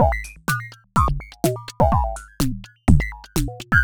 tx_perc_125_ringmodonit.wav